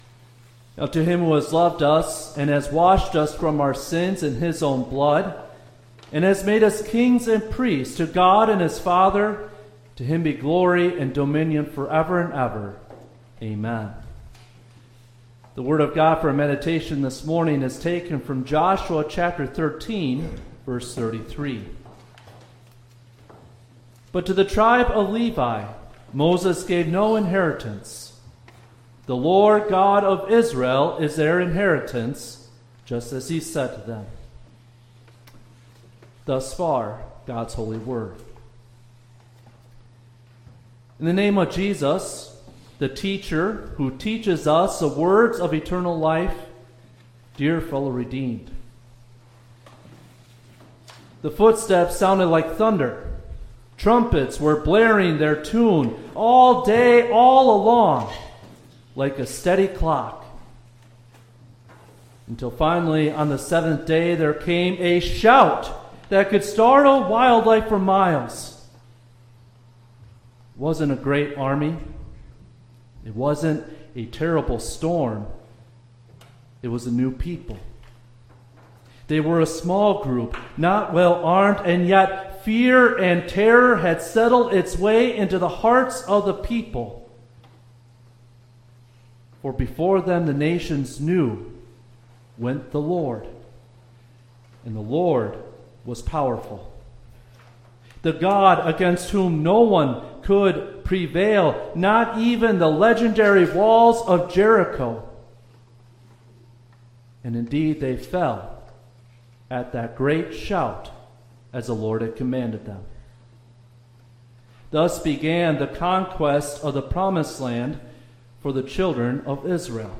Christian-Education-Sunday-Service.mp3